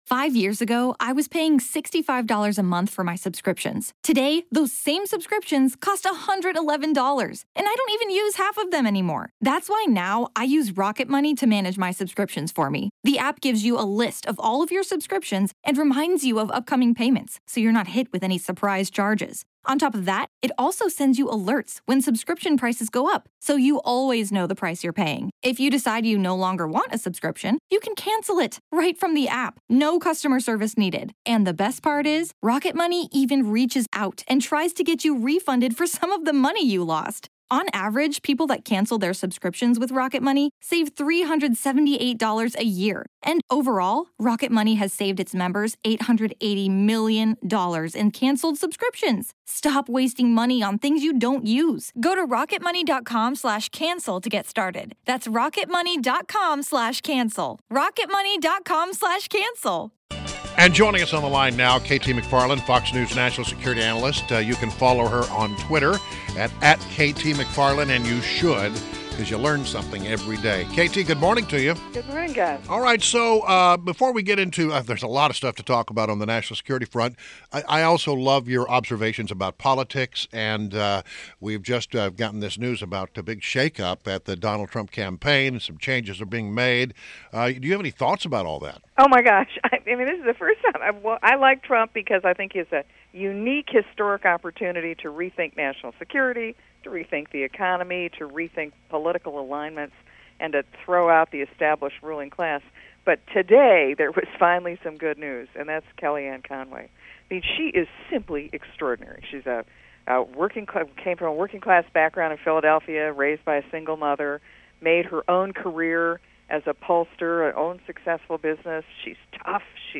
WMAL Interview - KT MCFARLAND - 08.17.16